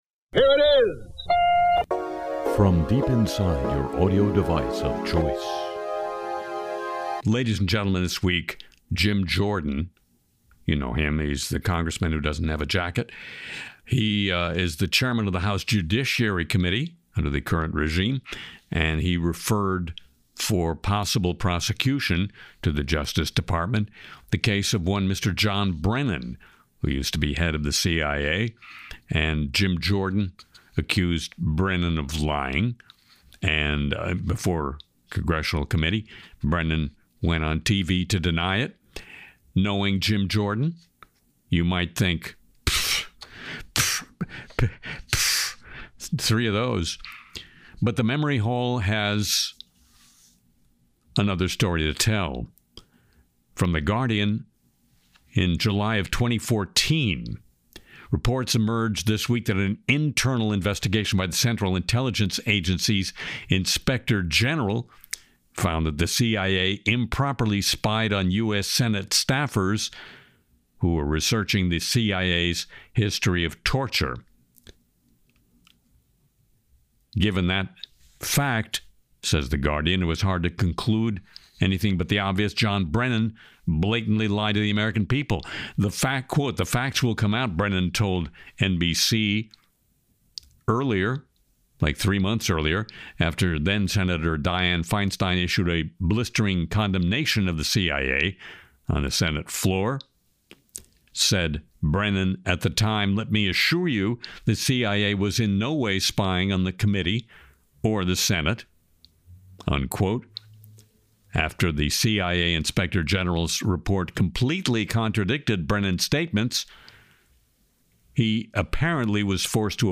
Harry Shearer’s Le Show (Oct 26 2025) Harry sings “Too Fat to Fight,” ICE fitness fails, Trump crypto empire, AI browser blunders, and Musk’s ‘Mad Max’ Tesla troubles.